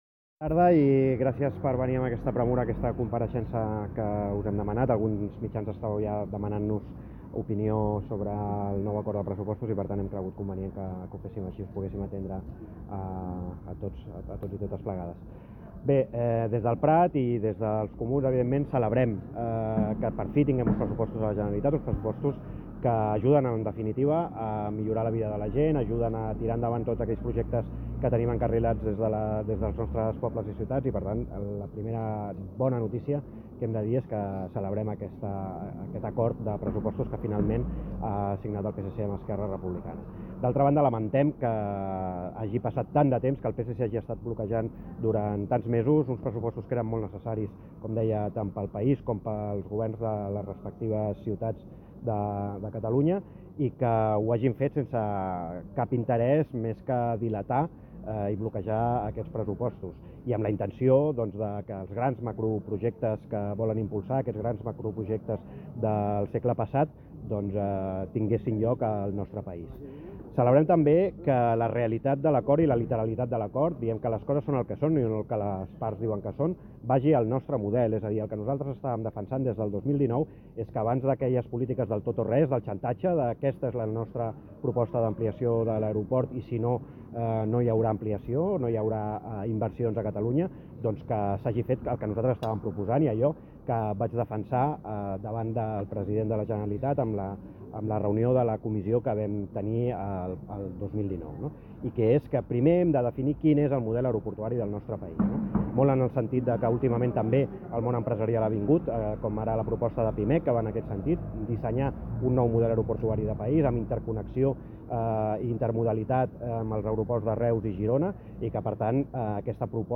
ADJUNTEM ARXIU SONOR DE LES DECLARACIONS.
declaracionslluismijoler_aeroport.mp3